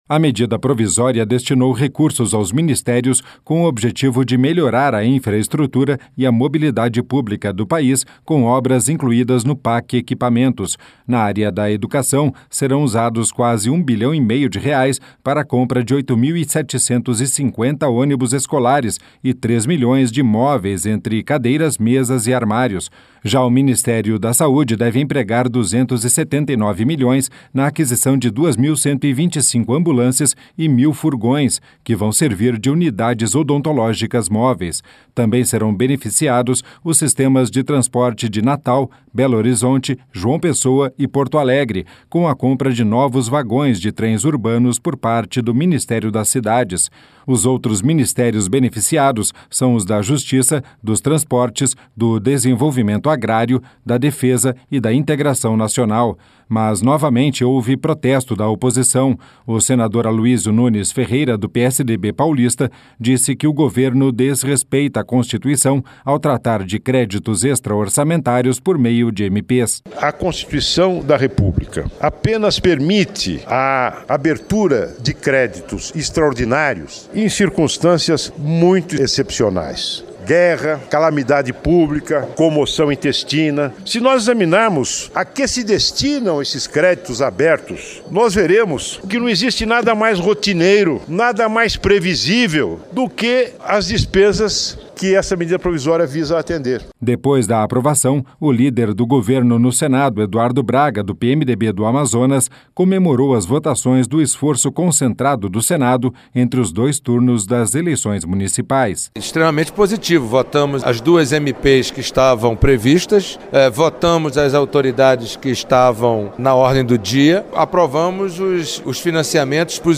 O senador Aloysio Nunes Ferreira, do PSDB paulista, disse que o governo desrespeita a Constituição ao tratar de créditos extra-orçamentários por meio de MPs.